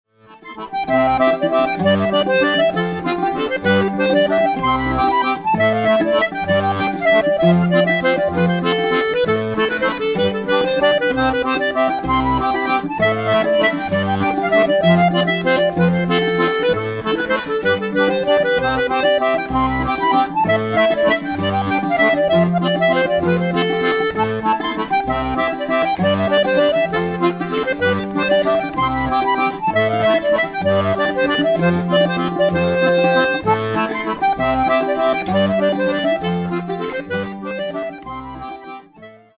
(valse)
Clarinette,Clarinette Basse
Veuze
Guitare 6 et 12 cordes, Pieds
Violon